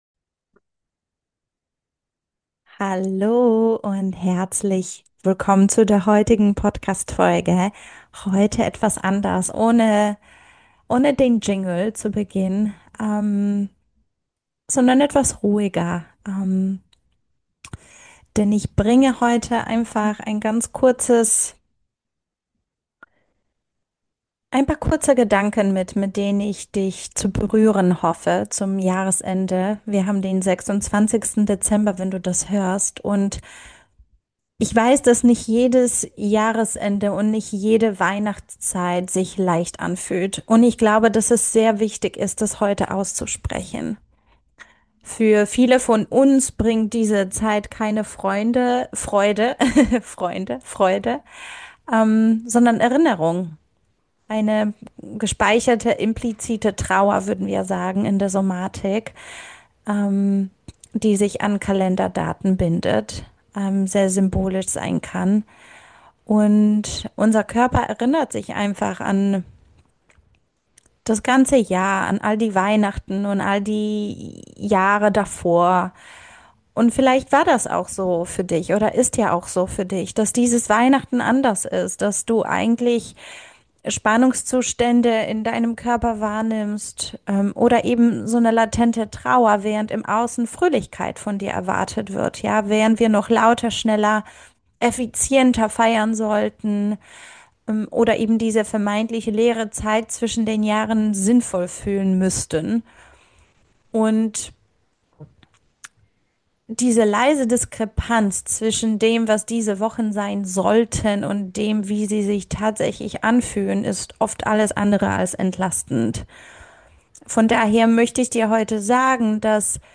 In dieser ruhigen Folge – ohne Jingle, ohne Eile – teile ich ein paar Gedanken zum Jahresende.